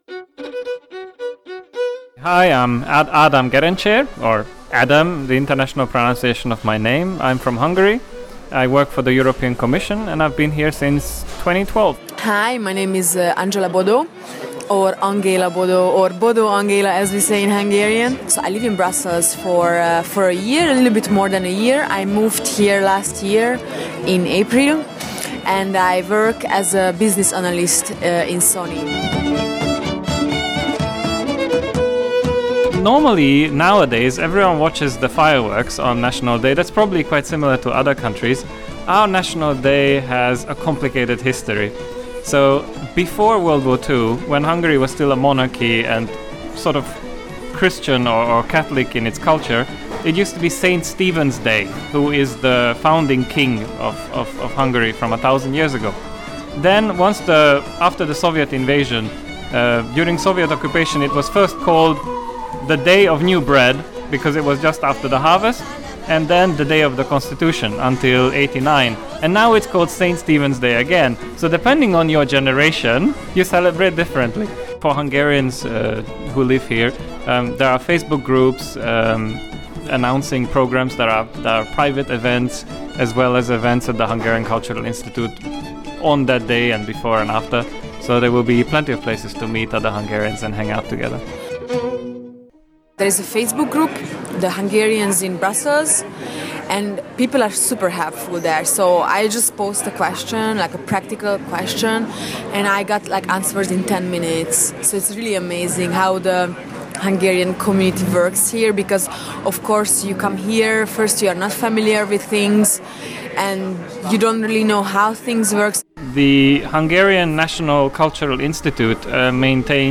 Hungarian expats in Brussels tell us about their national day (20 August) and where you can find a slice of Hungarian culture in the city